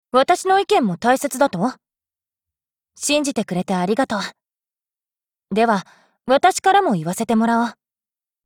贡献 ） 协议：Copyright，人物： 碧蓝航线:图林根语音 您不可以覆盖此文件。